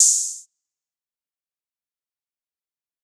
Wave Open Hat.wav